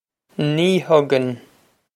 Pronunciation for how to say
Nee hug-in
This is an approximate phonetic pronunciation of the phrase.